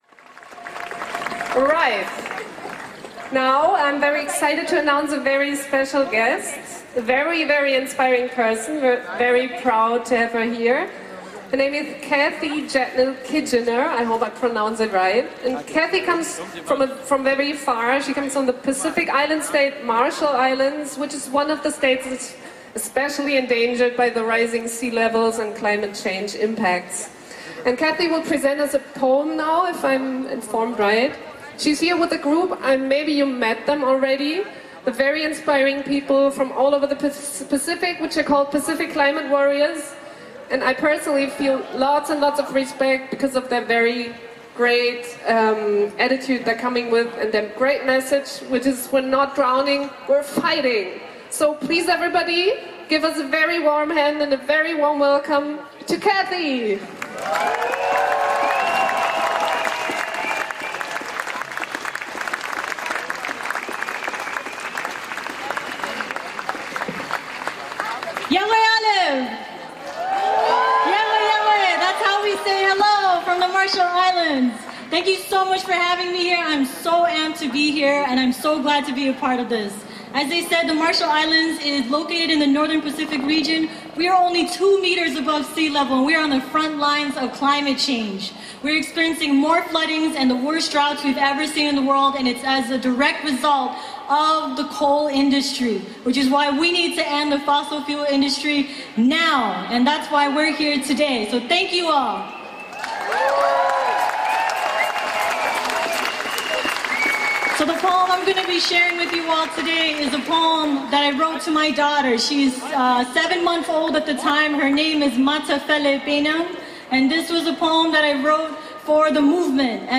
Kapitel 3: Abschlußkundgebung
Ansprache von Kathy Jetnil-Kijiner („Pacific Climate Warriors“[36, 37, 38]) (Audio 4/10) [MP3]